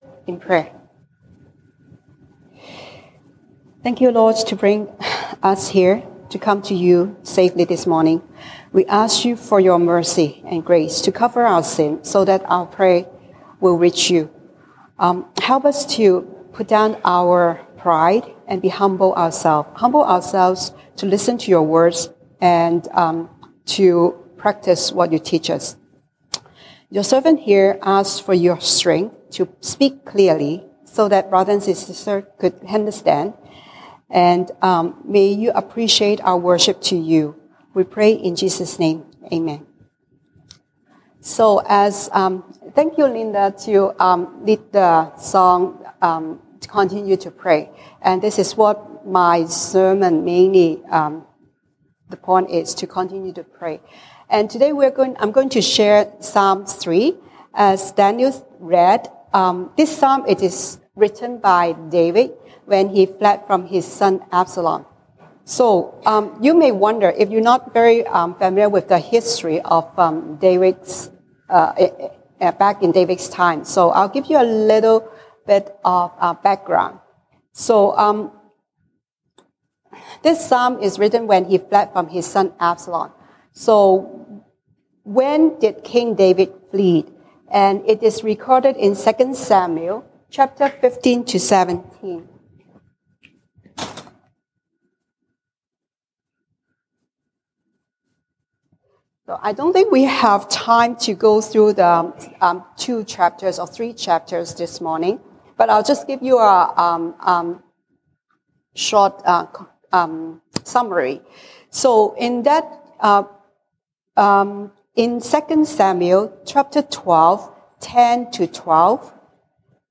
西堂證道 (英語) Sunday Service English: Prayer for Deliverance
Passage: 詩篇 Psalms 3:1-8 Service Type: 西堂證道 (英語) Sunday Service English